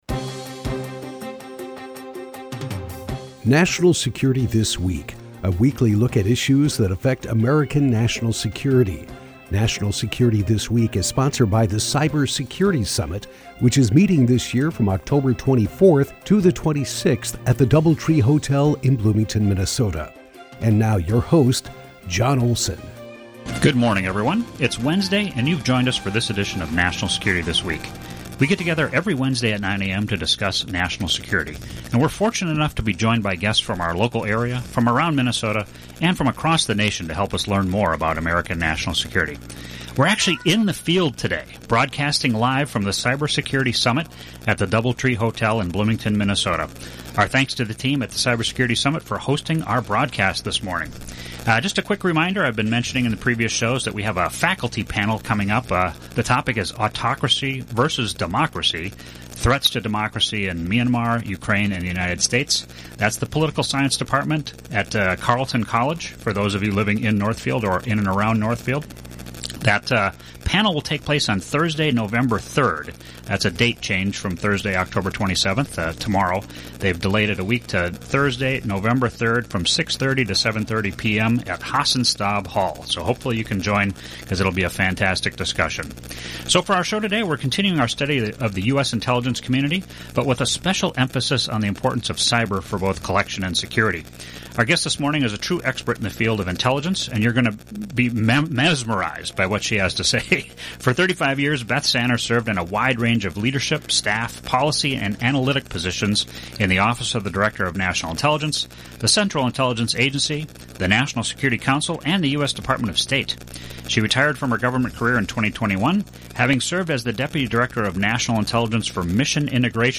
guest is Beth Sanner, former Deputy Director of National Intelligence for Mission Integration. They discuss the US Intelligence Community and cyber threats to American national security.